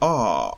ɑ-03-Open_back_unrounded_vowel.ogg.mp3